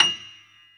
55p-pno37-F6.wav